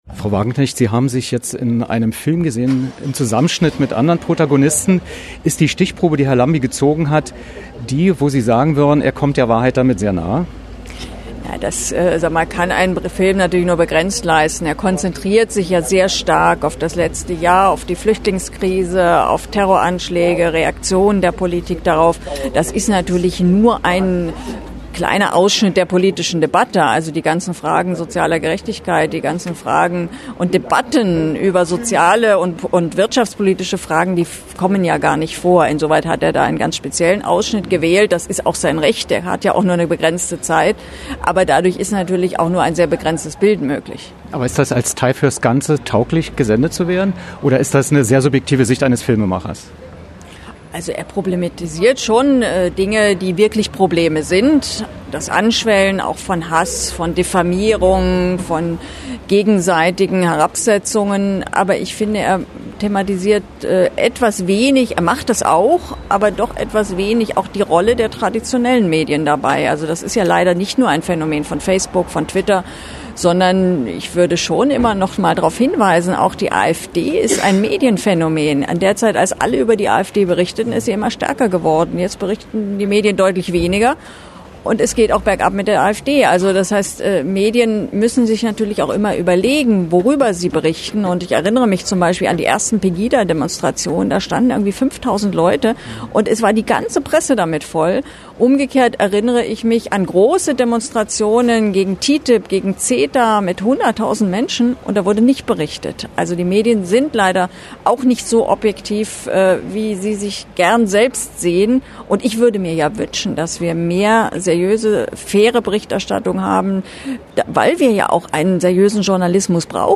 Was: Audio-Interview zum Dok-Film „Nervöse Republik“
Wo: Berlin, Kino Babylon